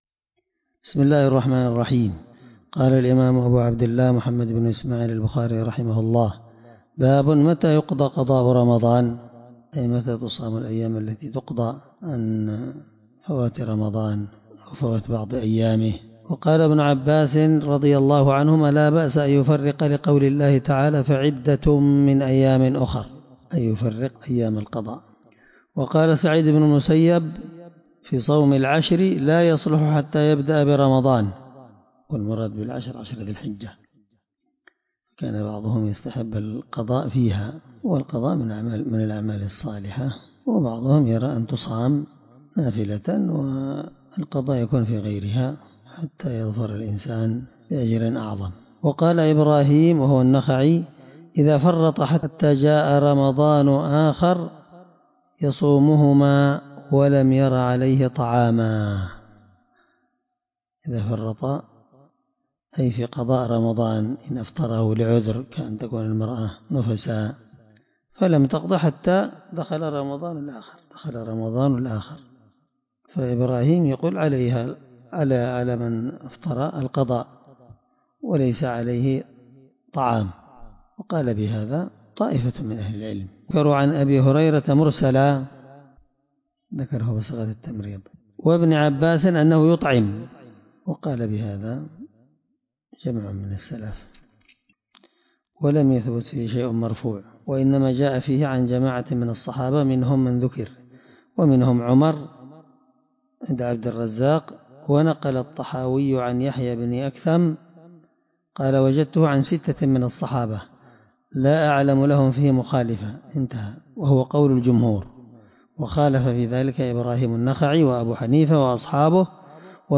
الدرس38من شرح كتاب الصوم حديث رقم(1950)من صحيح البخاري